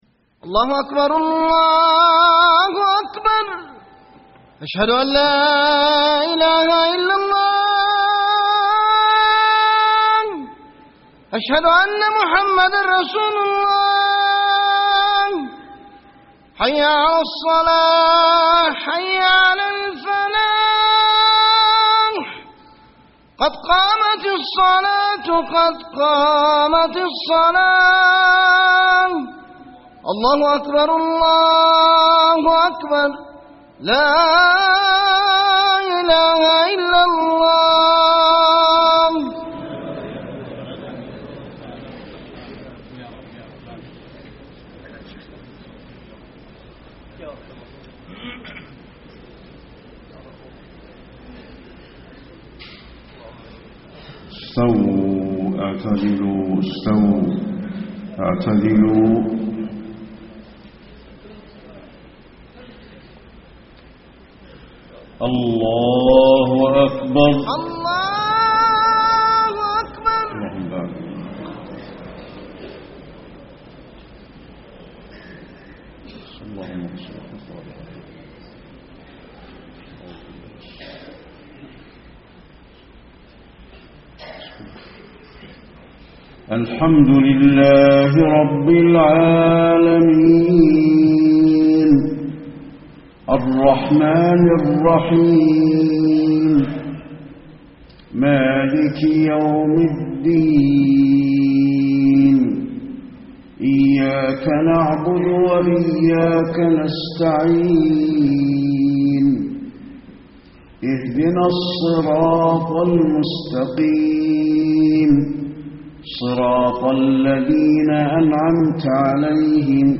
صلاة العشاء 2-7-1434هـ سورة النبأ > 1434 🕌 > الفروض - تلاوات الحرمين